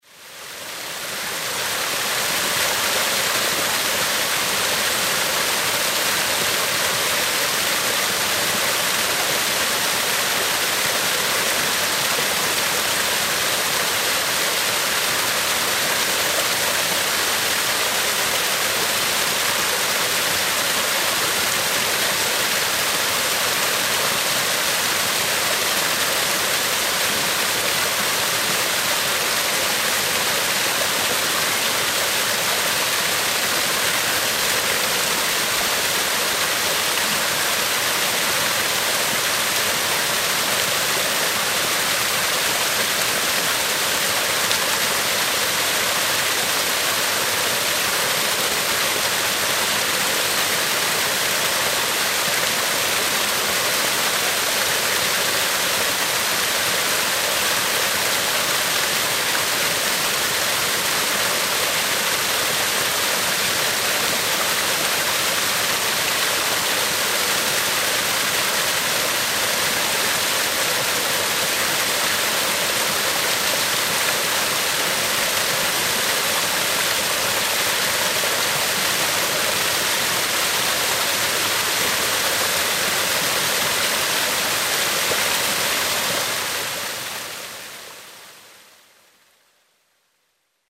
Gebirgsbach: